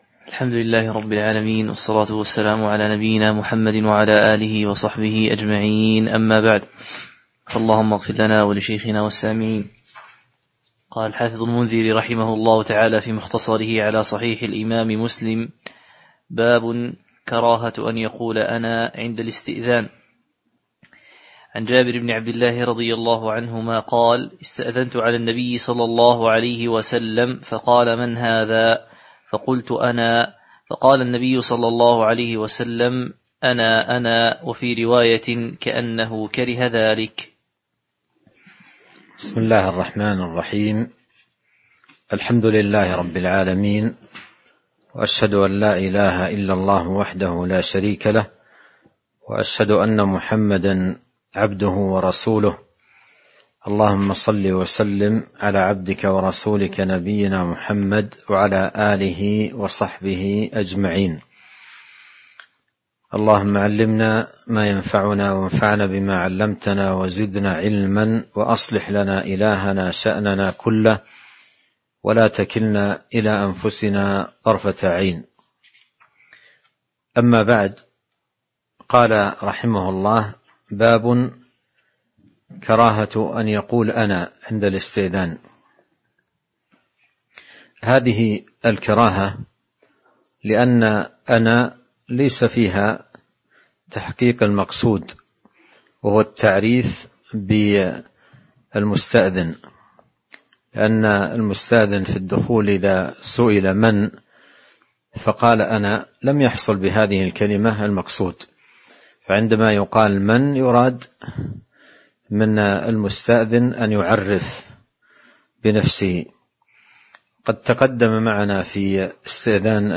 تاريخ النشر ١٣ رجب ١٤٤٣ هـ المكان: المسجد النبوي الشيخ